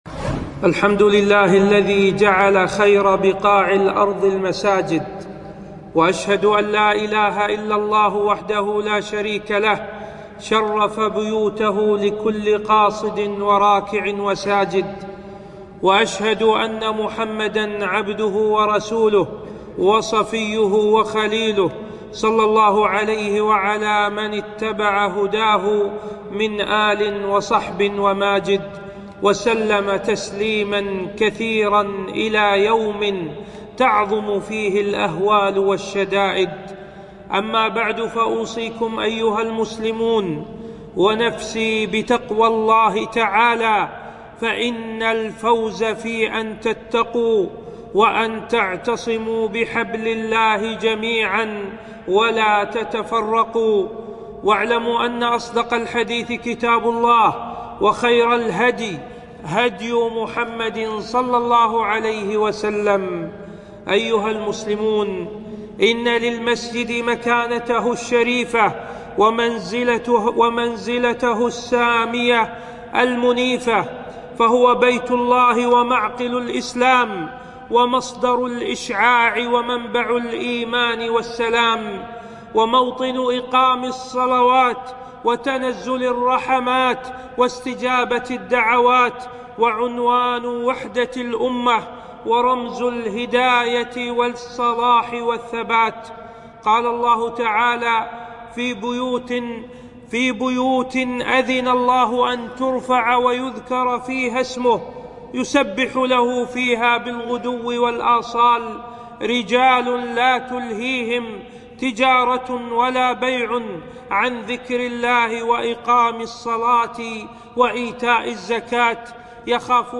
خطبة - مكانة المسجد في الإسلام